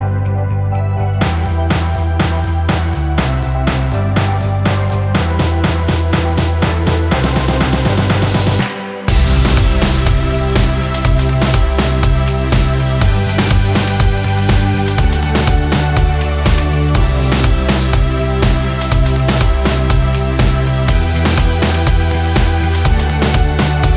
Minus Guitars Pop (2010s) 3:40 Buy £1.50